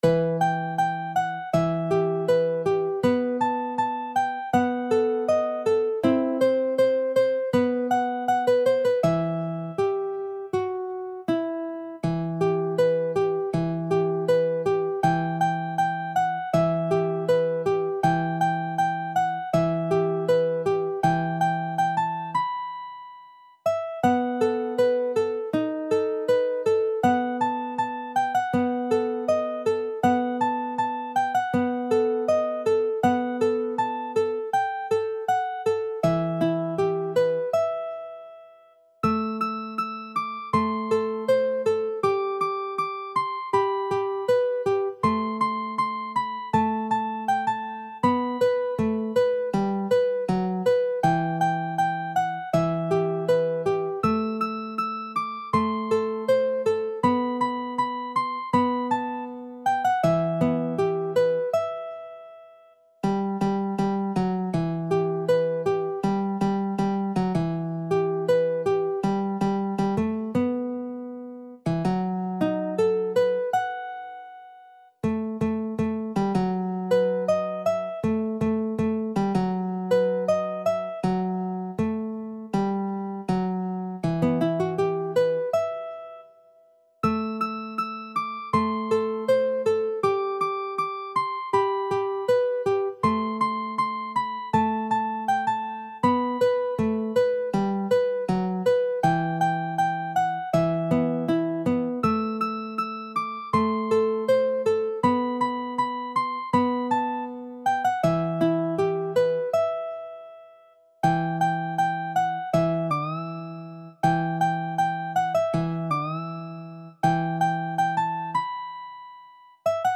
歌謡曲・演歌
楽譜の音源（デモ演奏）は下記URLよりご確認いただけます。
（この音源はコンピューターによる演奏ですが、実際に人が演奏することで、さらに表現豊かで魅力的なサウンドになります！）